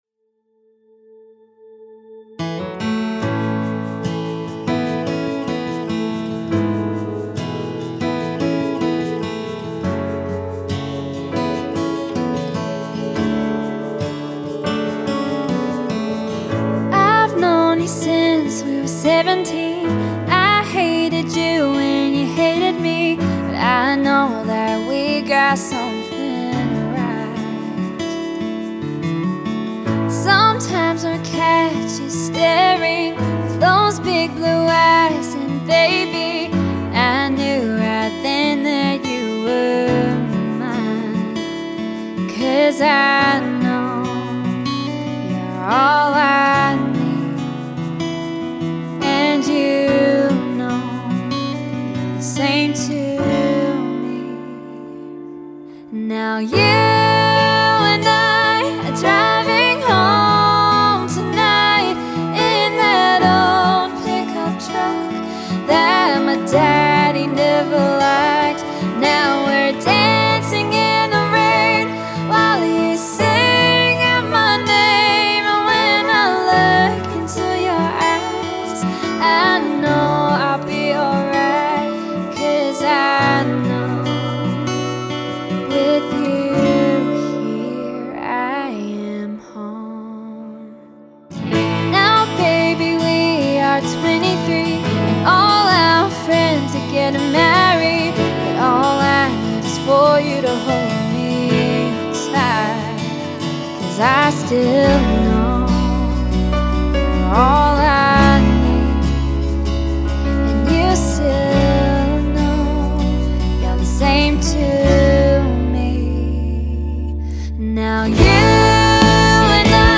Southland based singer songwriter